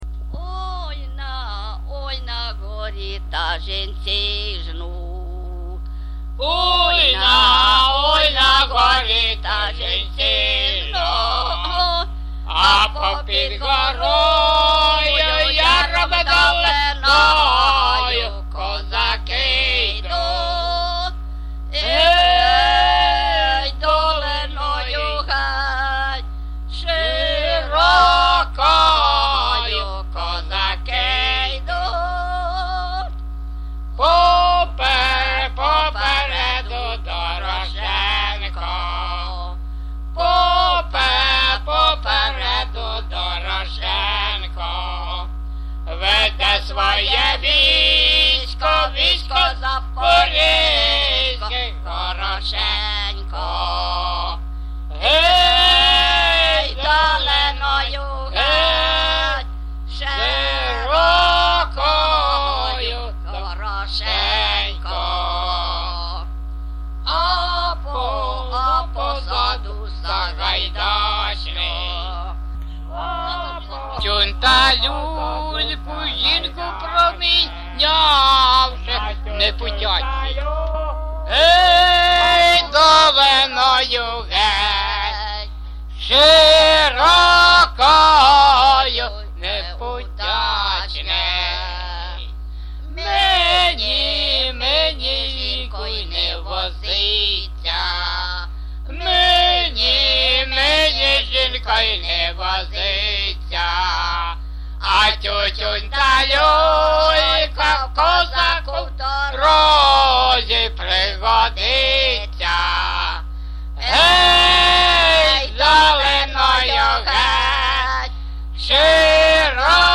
ЖанрКозацькі, Історичні
Місце записус. Нижні Рівні, Чутівський район, Полтавська обл., Україна, Слобожанщина